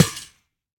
Minecraft Version Minecraft Version latest Latest Release | Latest Snapshot latest / assets / minecraft / sounds / block / spawner / break1.ogg Compare With Compare With Latest Release | Latest Snapshot